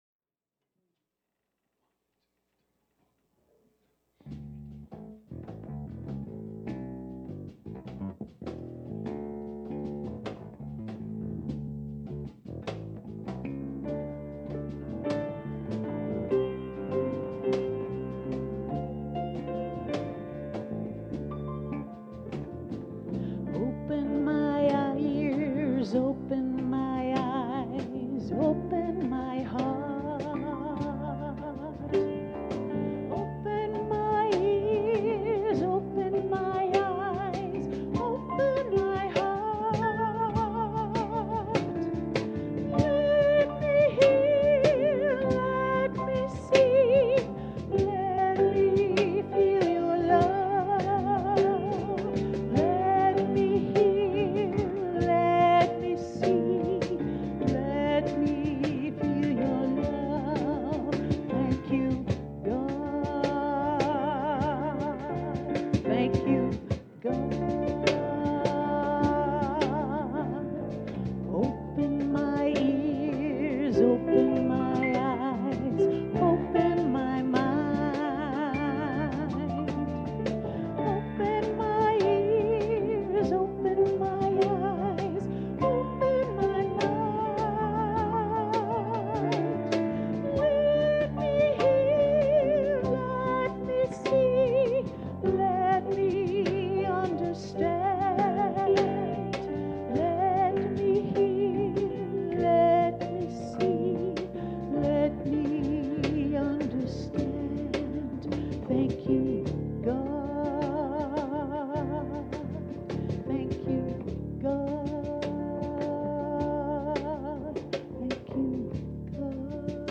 The audio recording below the video clip is an abbreviated version of the service. It includes the Meditation, Message, and Featured Song.